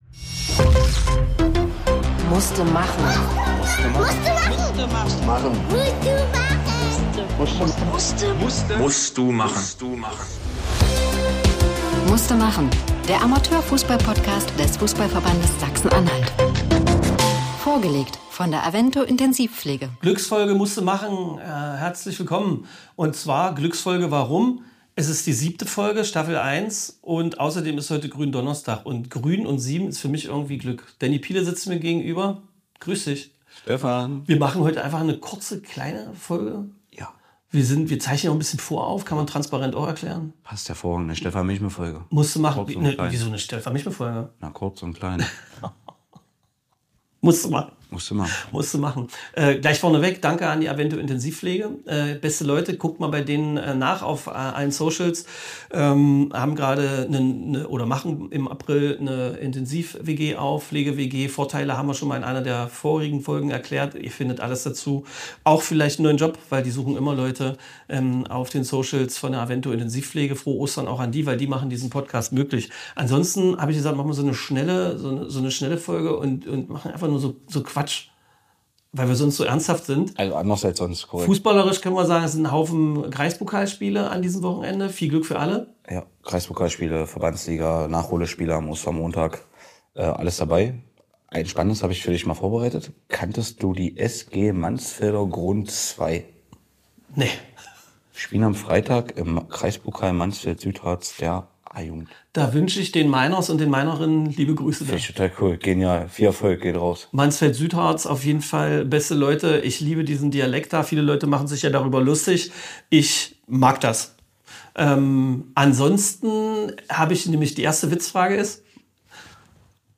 Die Grün-Donnerstag Impro rund um beste Fußball-Momente, besondere Vorkommnisse und wirkliche Stand Up Höhepunkte der beiden Amateurfußball-Komödianten.